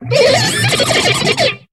Cri de Coatox dans Pokémon HOME.